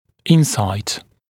[‘ɪnˌsaɪt][‘инˌсайт]понимание, проницательность, способность проникнуть в сущность, догадка